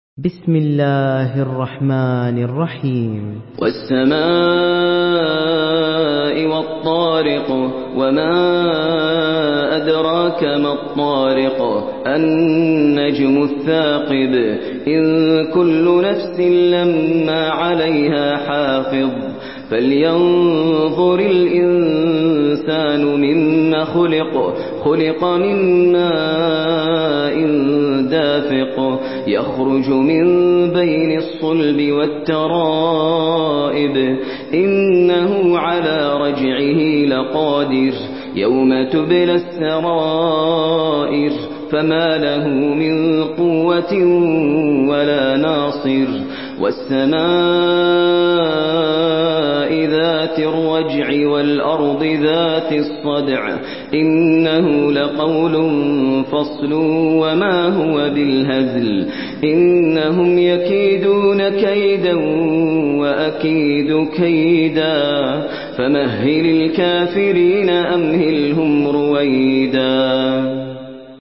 Surah الطارق MP3 in the Voice of ماهر المعيقلي in حفص Narration
Surah الطارق MP3 by ماهر المعيقلي in حفص عن عاصم narration.
مرتل